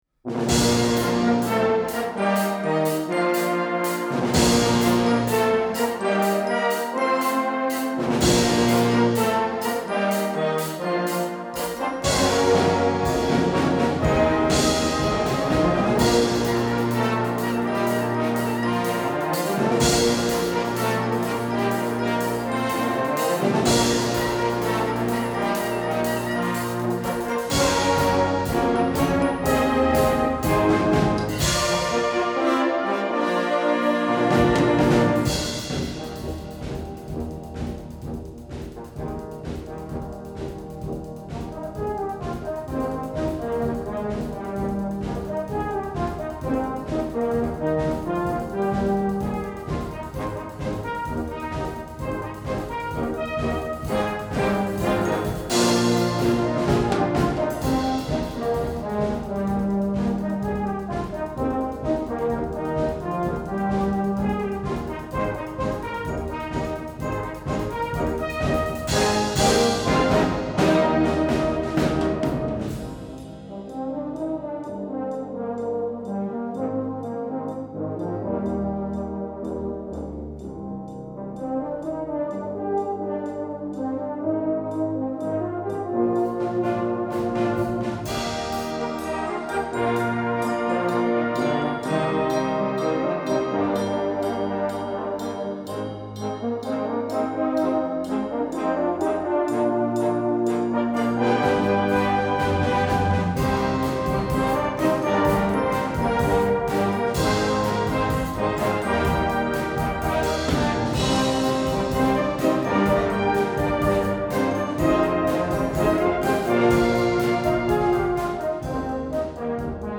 Gattung: Konzertwerk
A5-Quer Besetzung: Blasorchester PDF